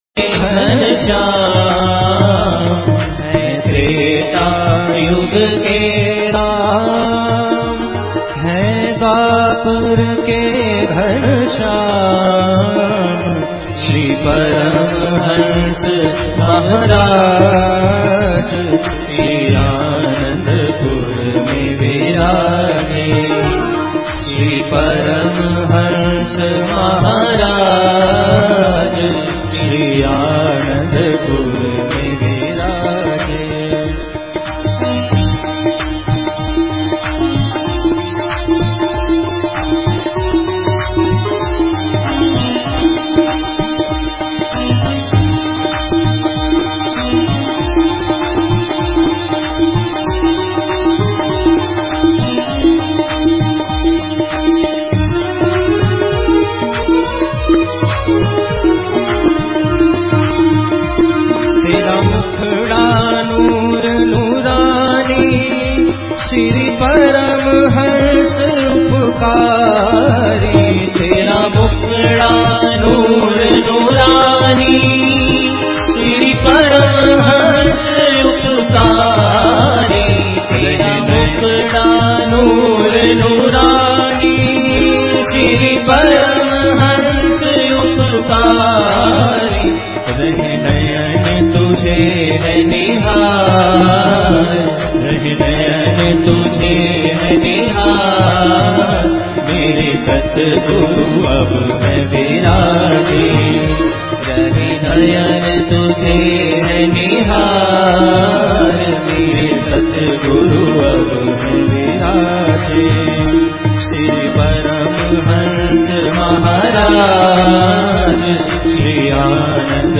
DOWNLOAD BHAJAN - EK TRETA YOG KE RAM